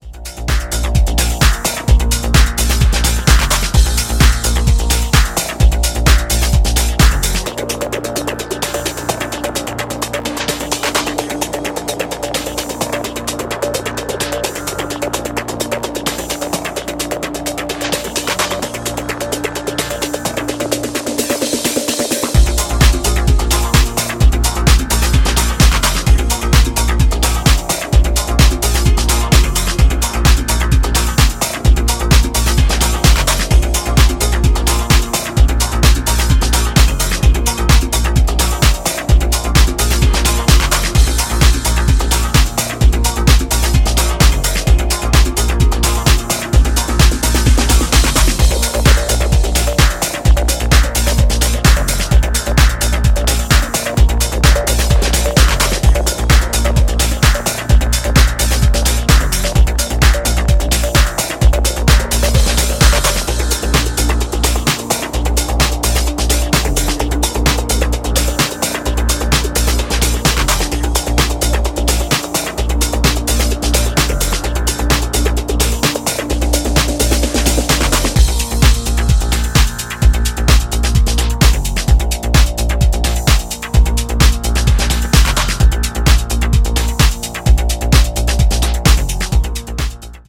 推進力抜群のエネルギーとサイケデリックなテクスチャーが共存した精彩なプロダクションが光ります。